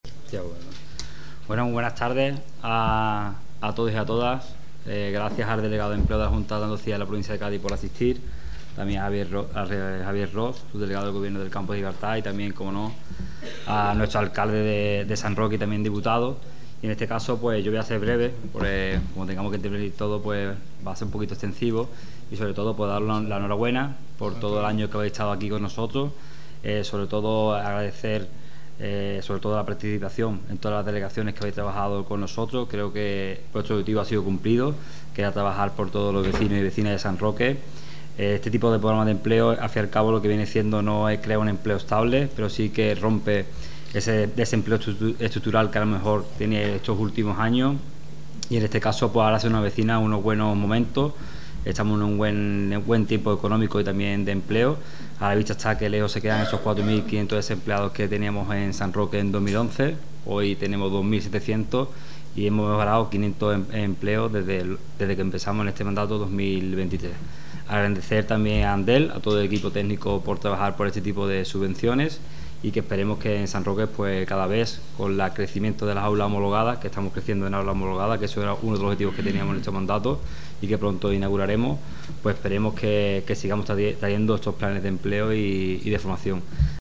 Acto de clausura del programa de empleo ConectaDoc